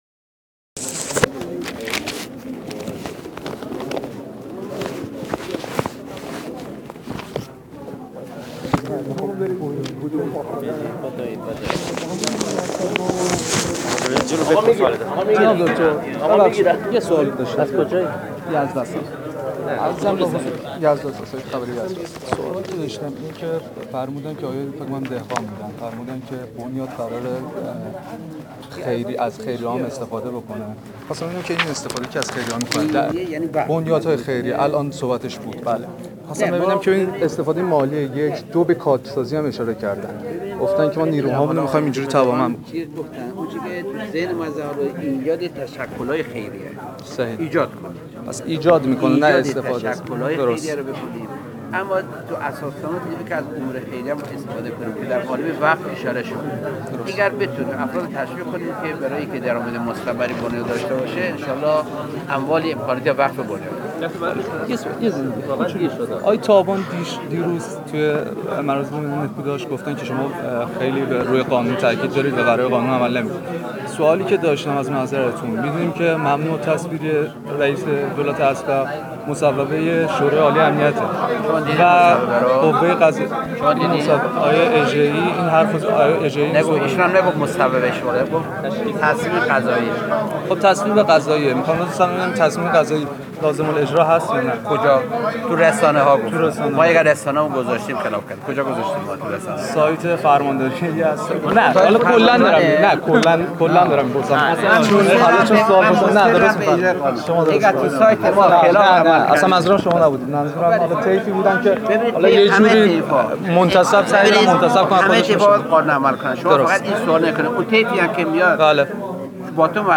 دکتر محمد رضاعارف در حاشیه افتتاح دفتر بنیاد ایرانیان یزد در مصاحبه با خبرنگار یزد رسا بر نقش بنیاد های خیریه در توانمند سازی نیروهای این بنیاد تاکید کرد و اظهار داشت: تشویق افراد در کسب ، دانش‌ و مهارت‌های  درامد مستمر را برای بنیاد فراهم می کند.